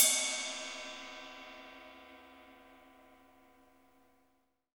Index of /90_sSampleCDs/Roland L-CD701/CYM_Rides 1/CYM_Ride menu
CYM ROCK 0JL.wav